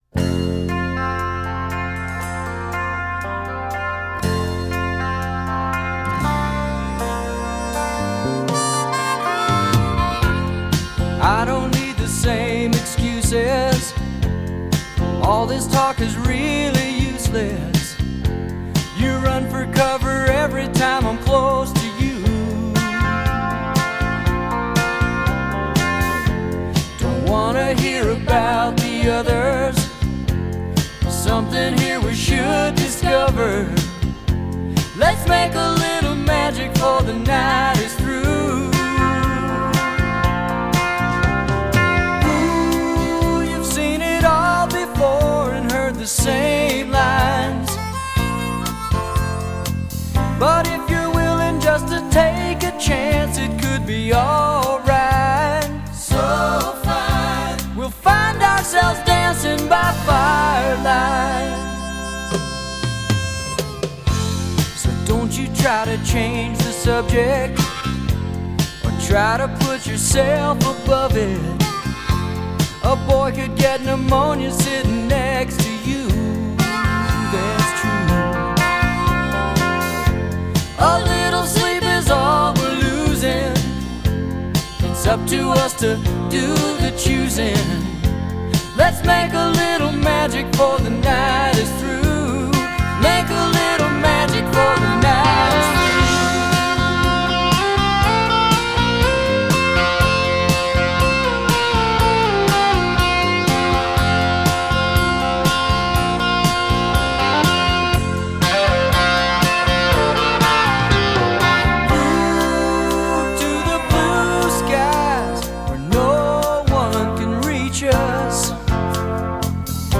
una banda de country, rock californianas